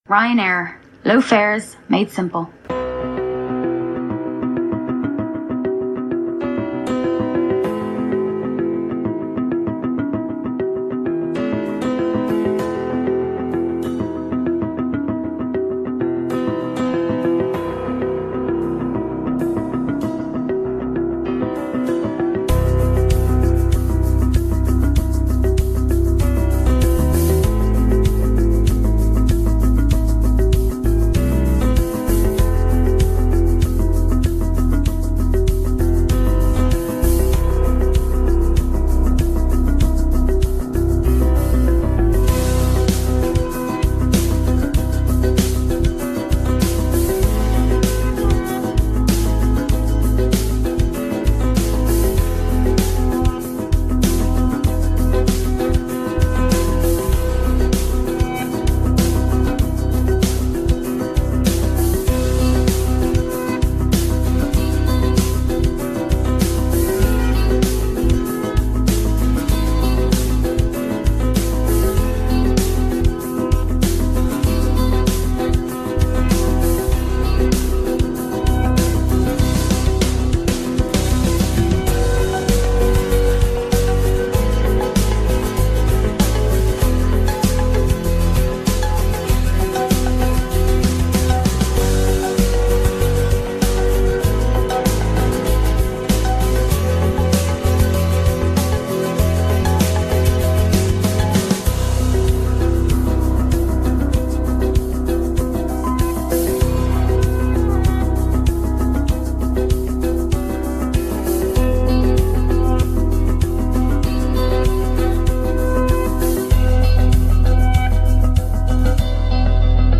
BoardingMusic[1][Evening].ogg